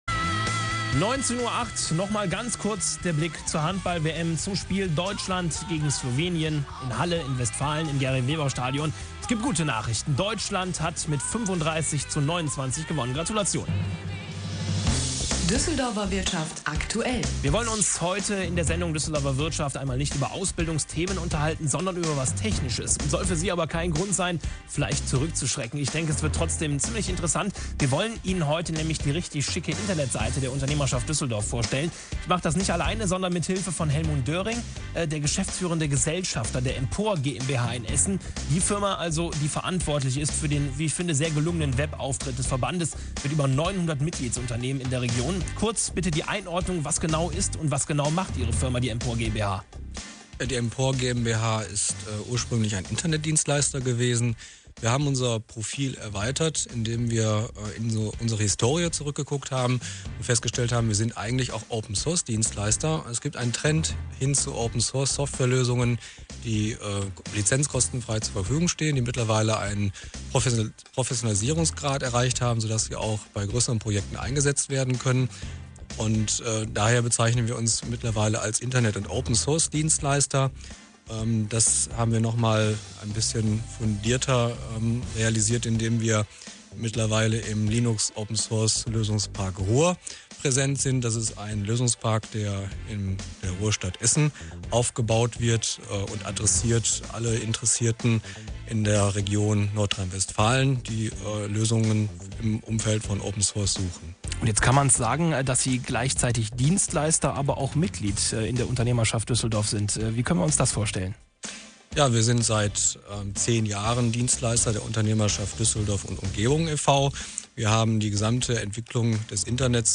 Radiointerview zum Wirtschaftsportal der Unternehmerschaft